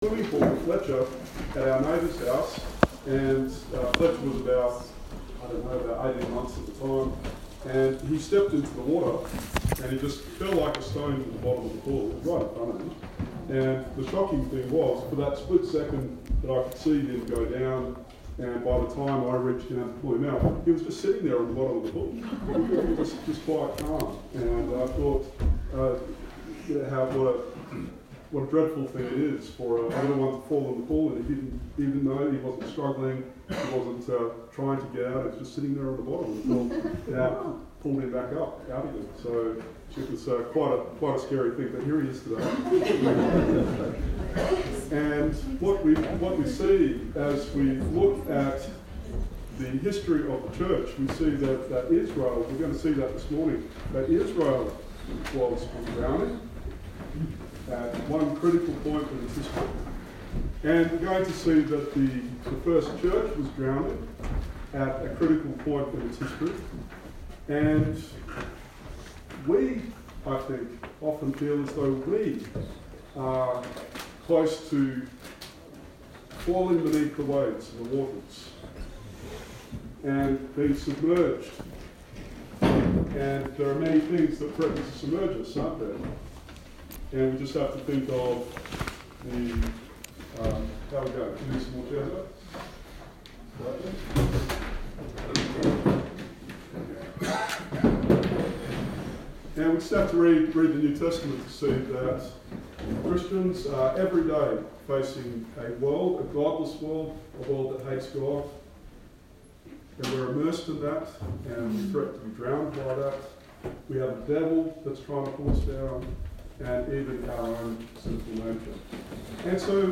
Genesis 1:6-8 Sermon